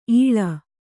♪ īḷa